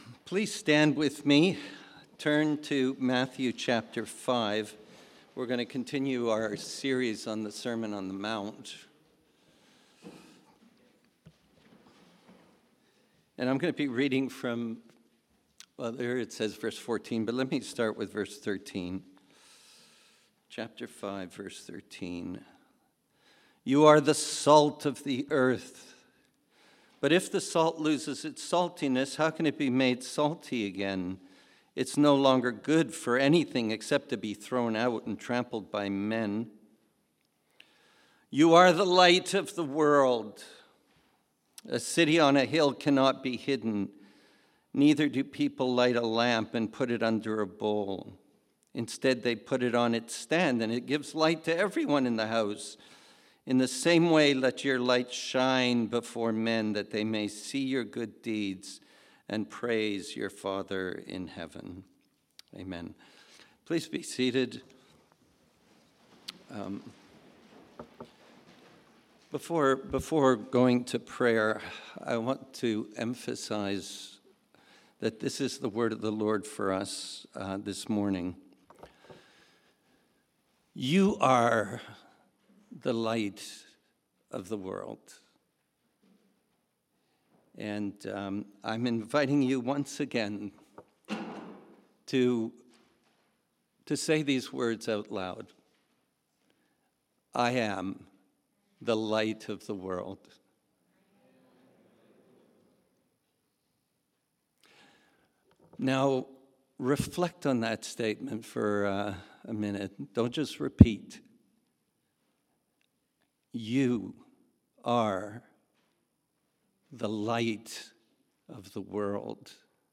Sermons | Mountainview Christian Fellowship